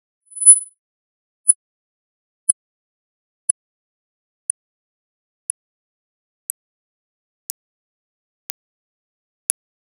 transients_10kHz.wav